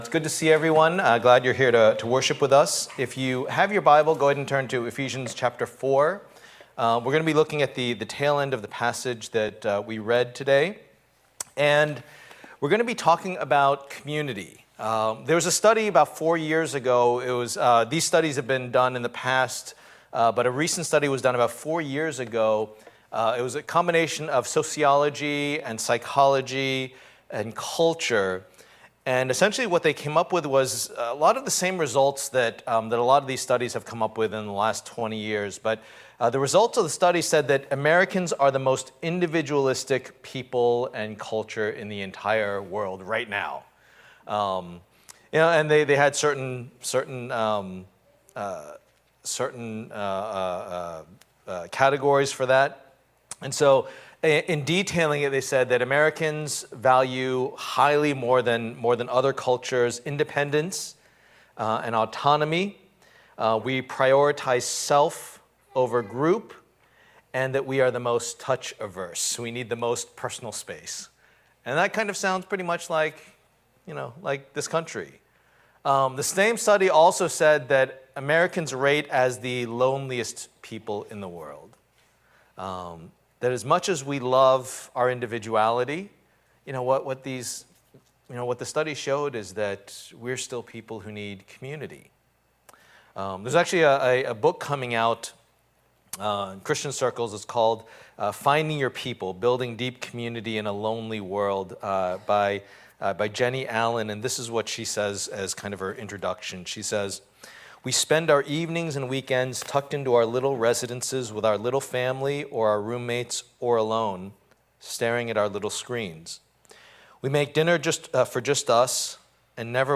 Passage: Ephesians 4:17-32 Service Type: Lord's Day